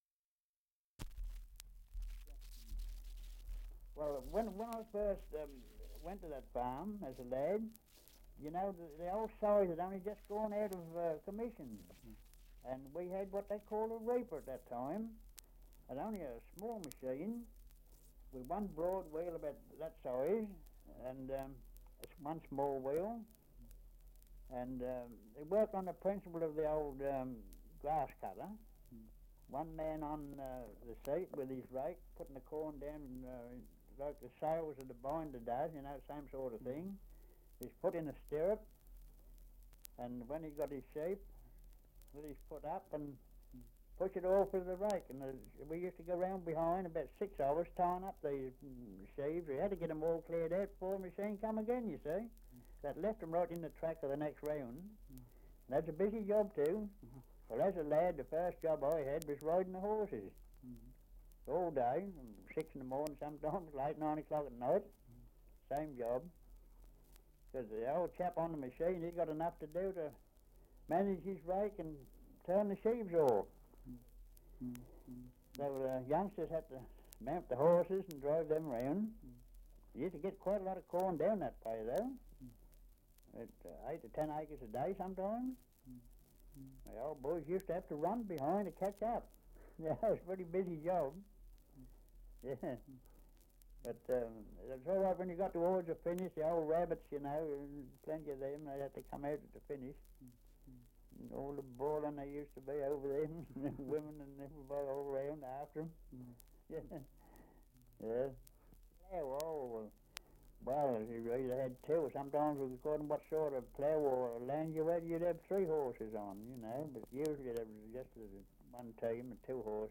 Survey of English Dialects recording in Little Baddow, Essex
78 r.p.m., cellulose nitrate on aluminium